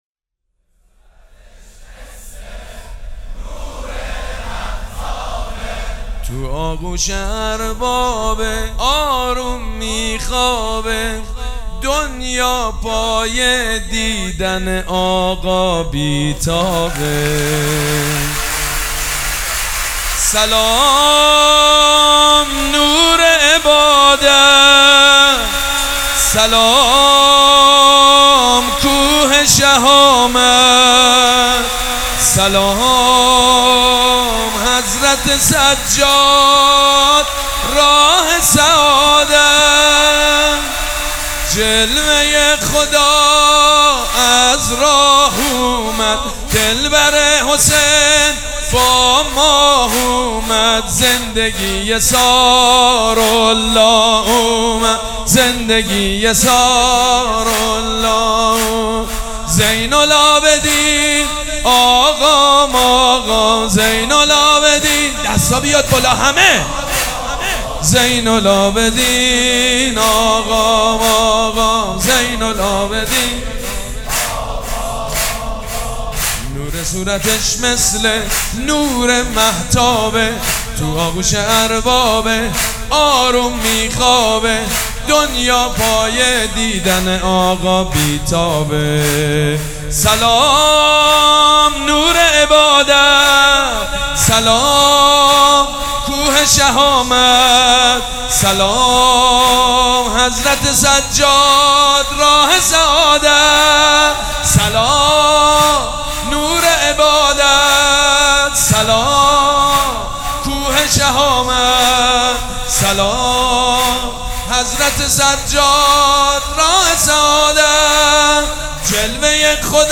مولودی
همزمان با فرارسیدن اعیاد شعبانیه و جشن میلاد حضرت امام سجاد علیه السلام فایل صوتی گلچین مولودی با نوای مداحان اهل بیت (ع) را می شنوید.